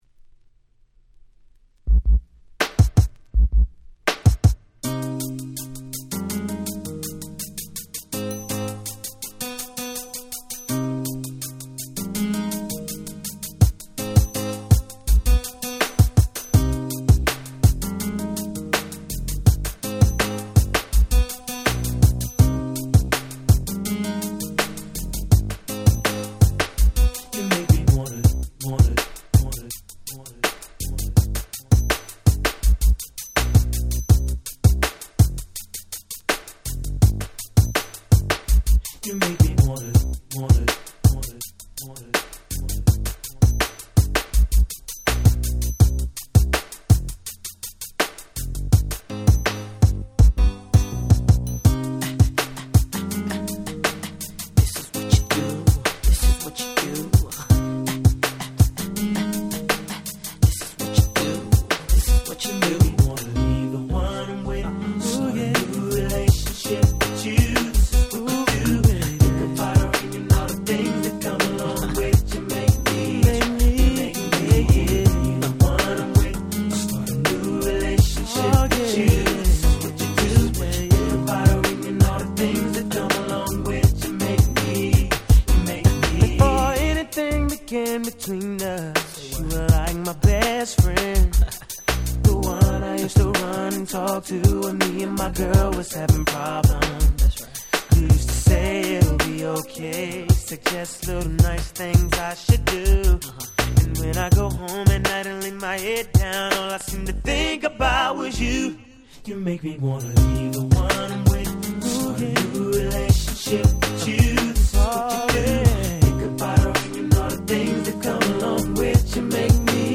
97' Super Hit R&B !!